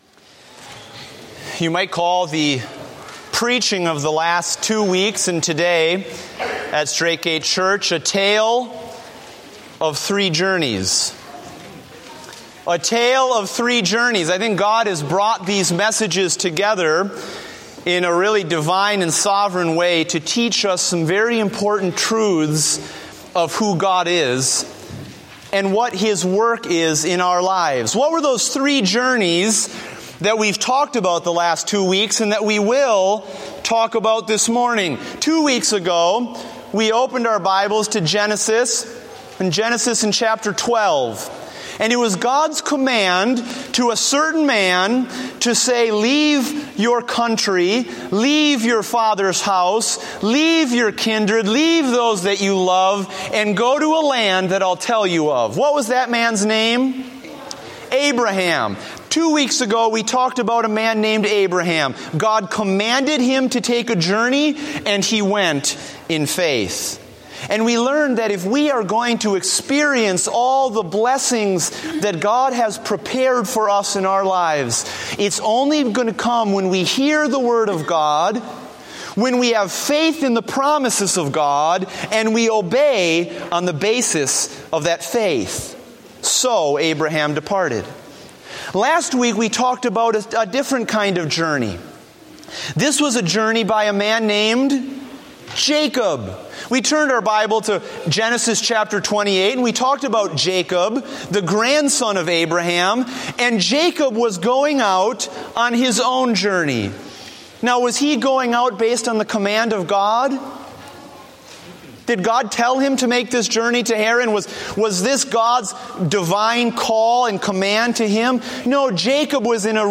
Date: January 25, 2015 (Morning Service)